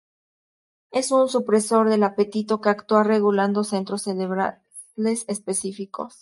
a‧pe‧ti‧to
/apeˈtito/